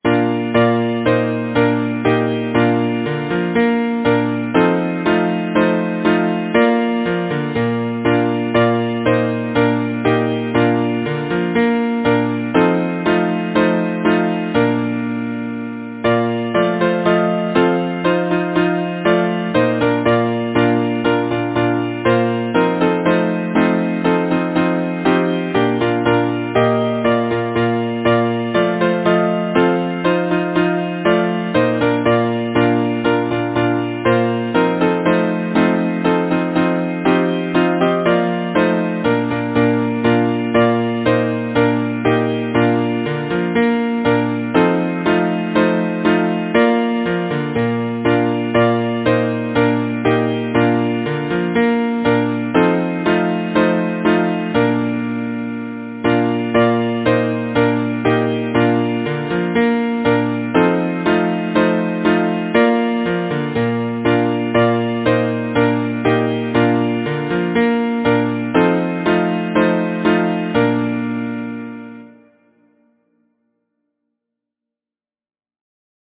Title: Spring Song Composer: James M. Dungan Lyricist: Nora Perry Number of voices: 4vv Voicing: SATB Genre: Secular, Partsong
Language: English Instruments: A cappella